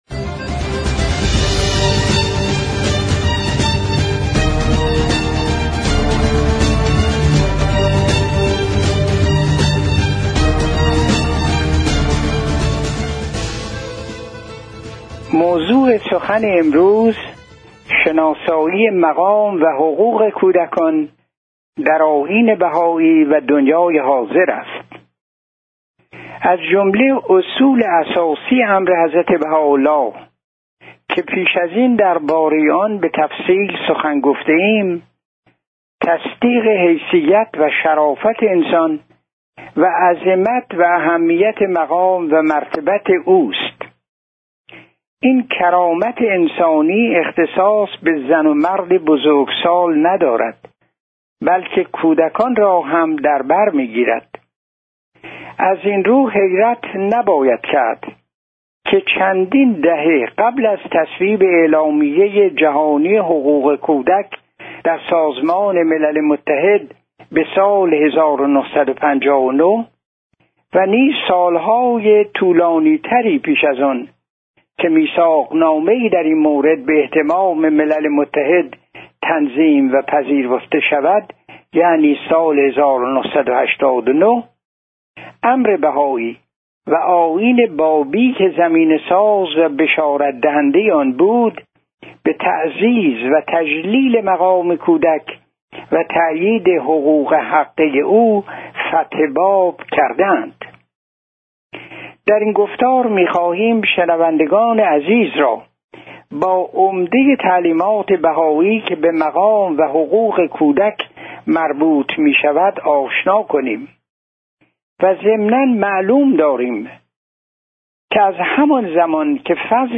دانلود گفتار هجدهم: نويد تغيير بنيادی در اوضاع جهان سایر دسته بندیها سخنرانی هایی درباره آئین بهائی